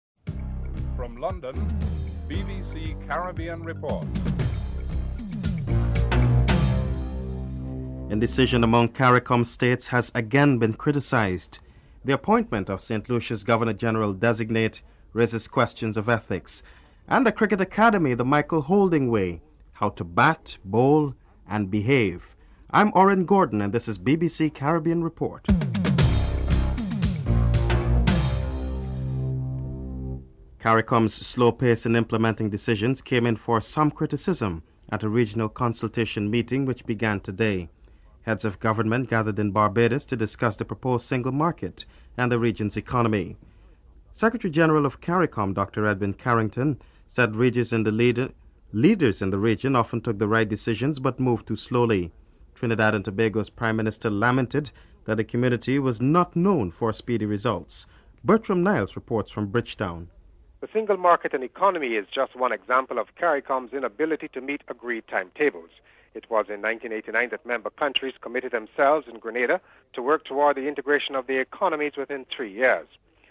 Caricom General Secretary Edwin Carrington, Prime Minster Basdeo Panday and Prime Minister Owen Arthur are interviewed (00:30-04:06)
Cricket Commentator Michael Holding is interviewed (11:17-15:22)